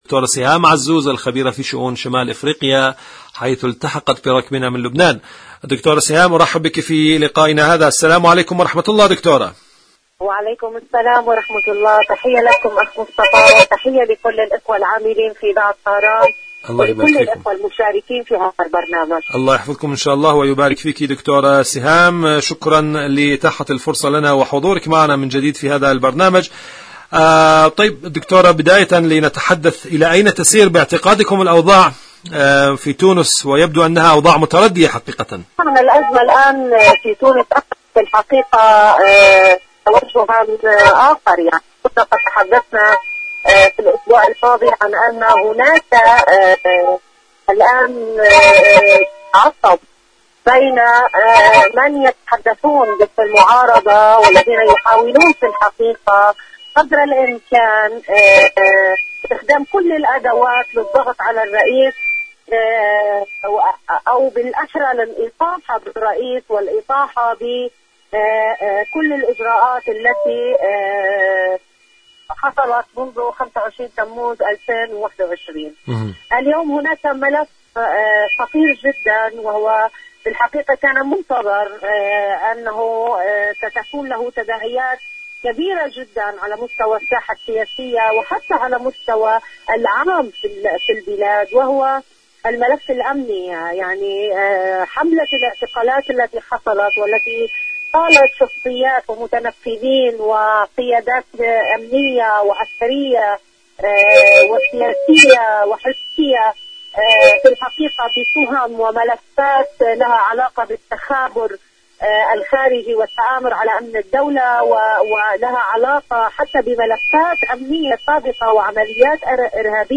مقابلات برامج إذاعة طهران العربية مقابلات إذاعية برنامج صدى المغرب العربي تونس الحكومة التونسية السجالات السياسية صدى المغرب العربي شاركوا هذا الخبر مع أصدقائكم ذات صلة الردع الإيراني والمقاومة الفلسطينية..